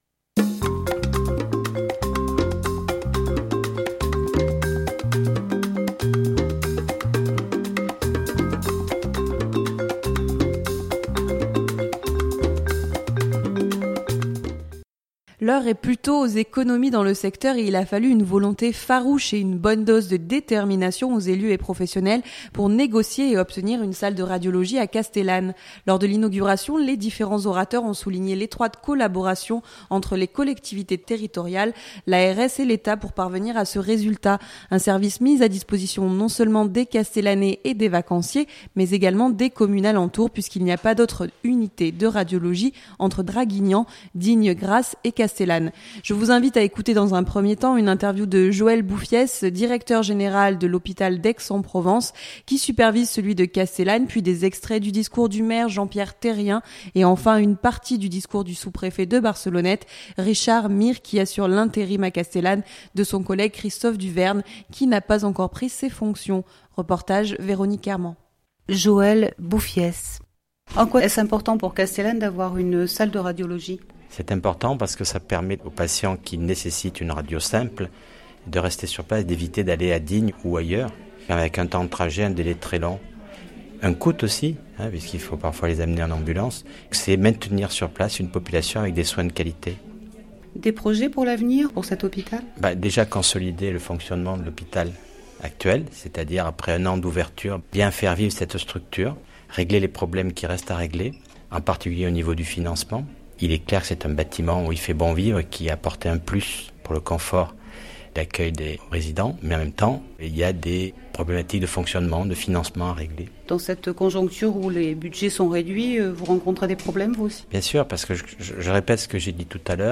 Lors de l’inauguration, les différents orateurs ont souligné l’étroite collaboration entre les collectivités territoriales, l’ARS et l’Etat pour parvenir à ce résultat. Un service mis à disposition, non seulement des Castellanais et des vacanciers mais également des communes alentours puisqu’il n’y pas d’autre unité de radiologie entre Draguignan, Digne, Grasse et Castellane.